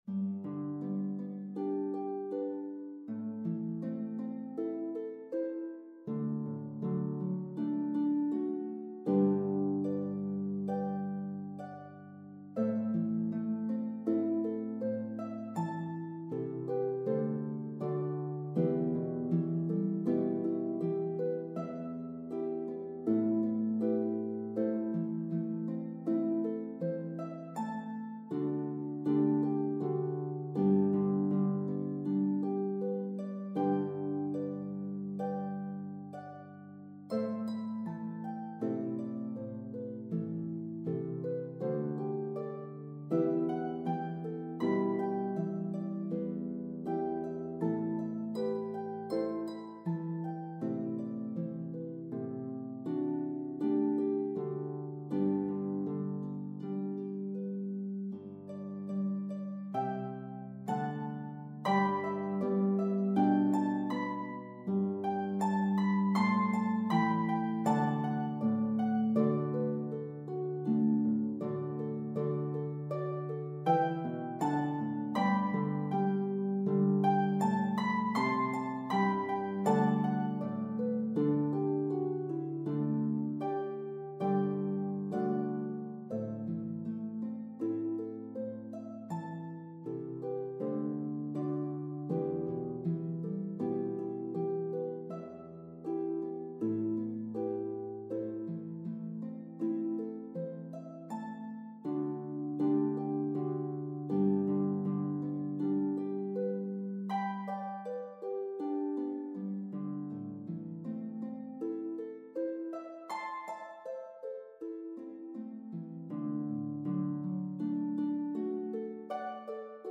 traditional Irish ballad
for lever or pedal harp duet or quartet
A Version Duet